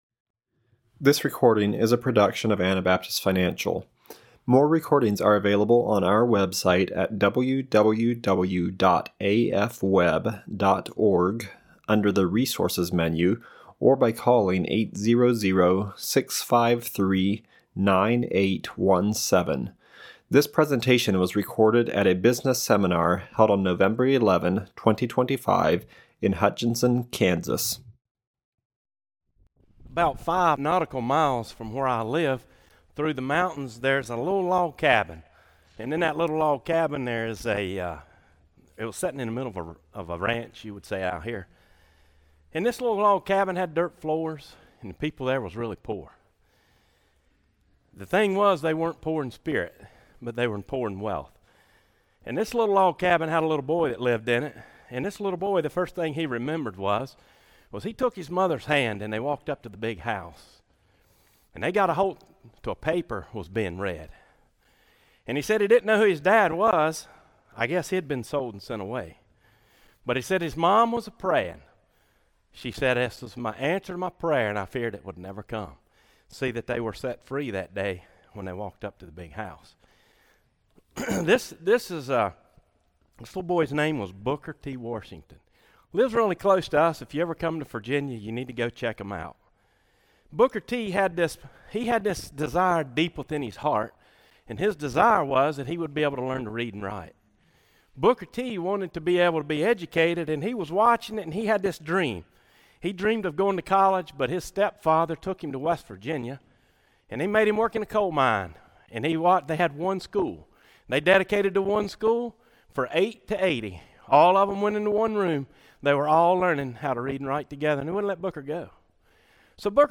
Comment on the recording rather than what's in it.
Kansas Business Seminar 2025